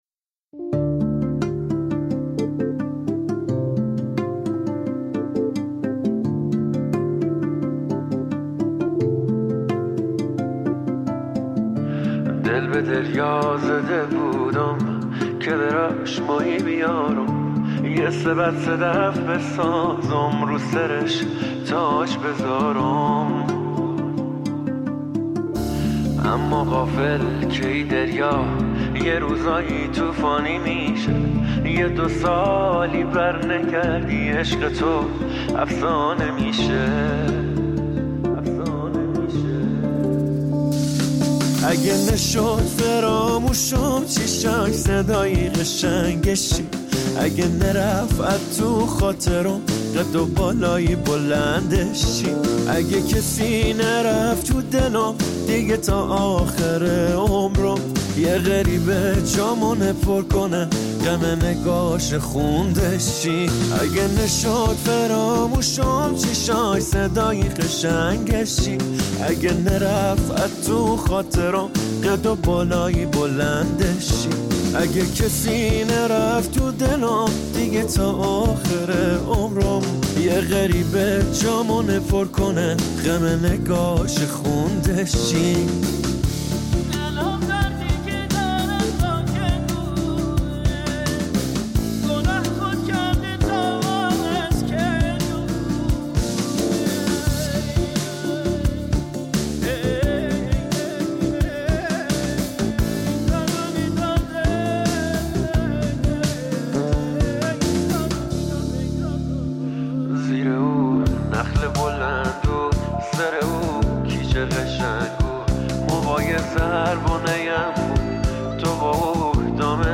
برچسب: آهنگ جدید آهنگ بندری